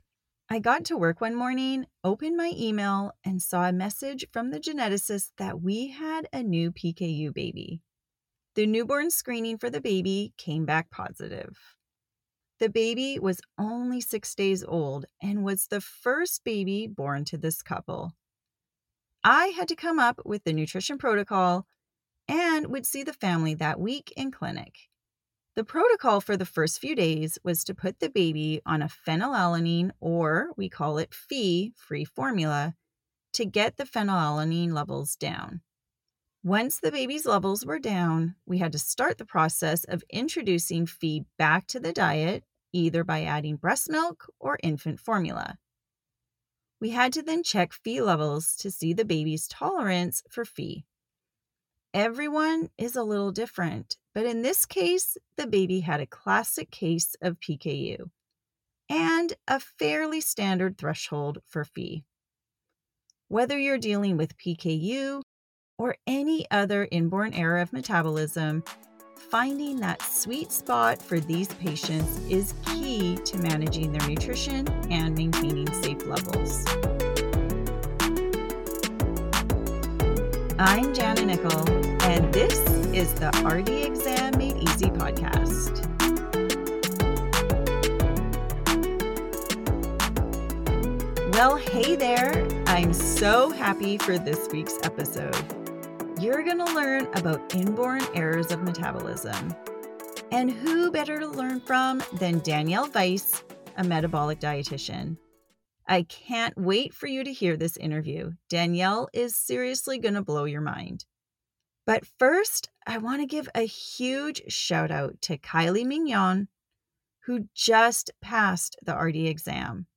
We have a great conversation about m…